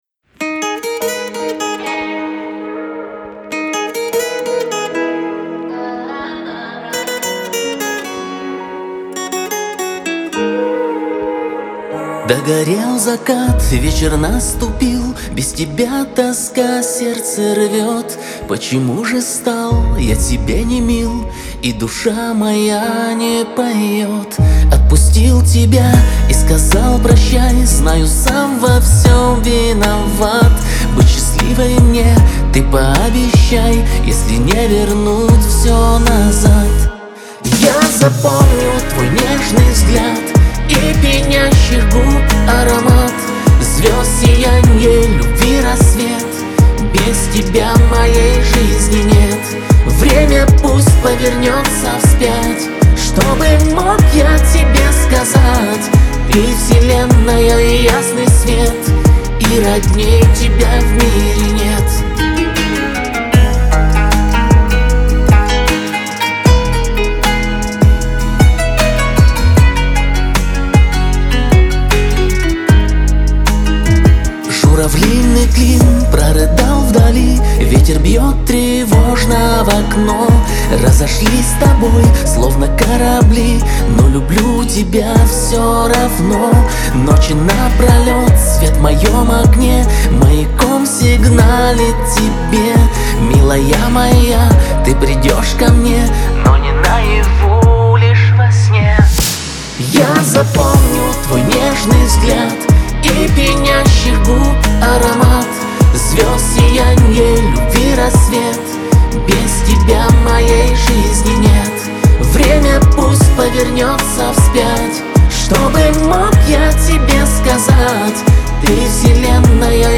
танцевальная музыка , эстрада
диско